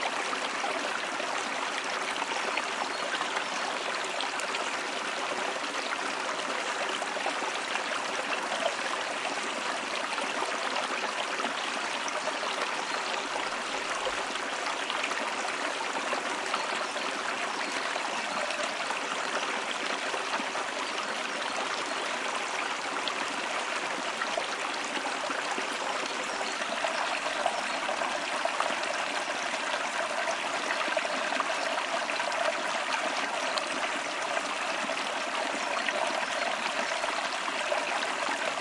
森林中的鸟鸣
描述：在2009年春季在瑞典西部的树林中实地记录鸟类。用索尼设备记录。
标签： 现场录音
声道立体声